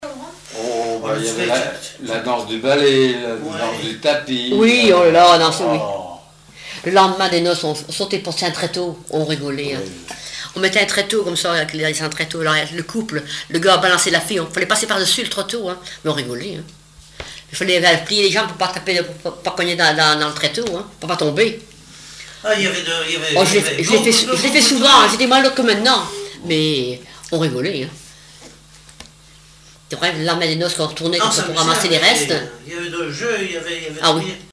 témoignage sur les noces
Catégorie Témoignage